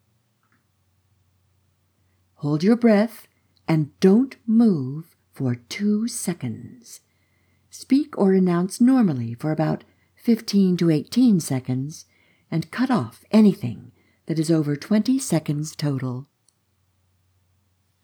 Effect > Normalize which is a fancy way of saying I made it louder (under very controlled conditions).
Then I applied the most gentle possible Noise Reduction.
There is still a little rain-in-the-trees fffffff in there and now I have it together enough to critically listen.
Do you hear the little pencil drop at 1/4 second?
I didn’t hear any room echo or other problems in there, so whatever you did for soundproofing seems to work.